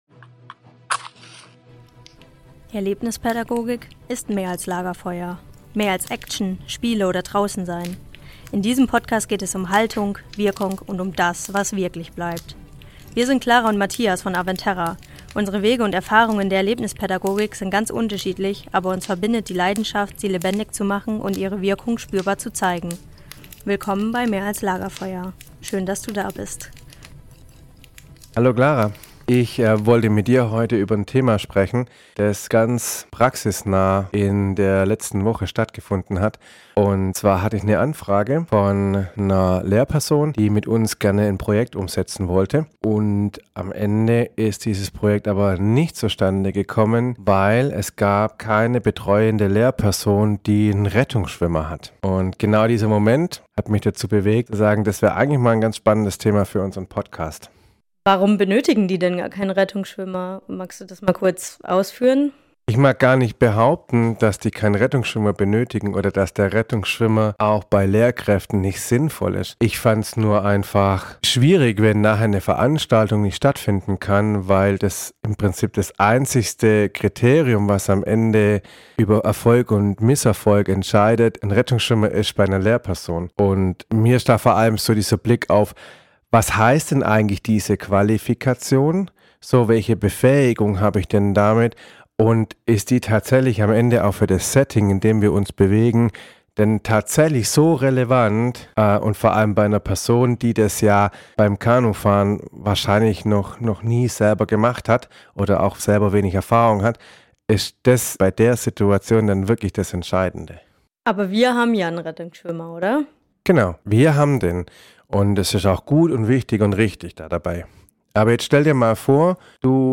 Ein ehrliches Gespräch über Verantwortung, Fachwissen und den Mut, sich immer wieder weiterzuentwickeln.